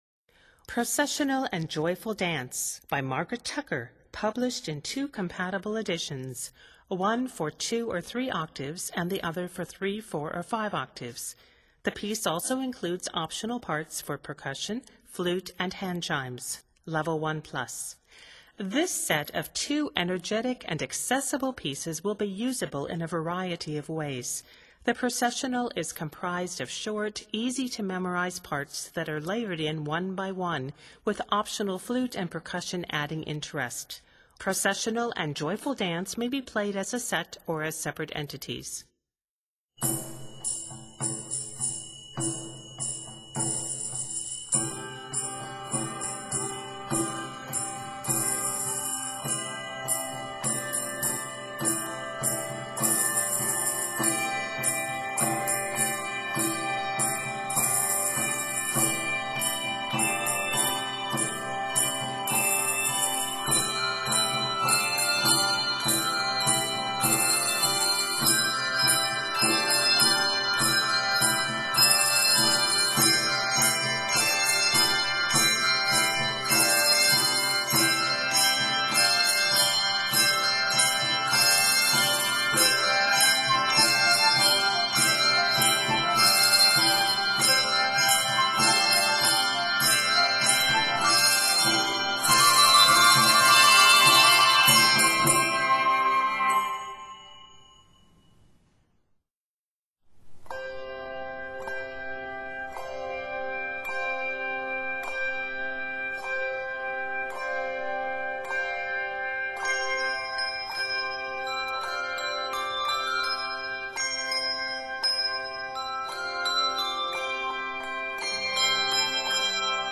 with optional flute and percussion adding interest.
Both works are scored in C Major.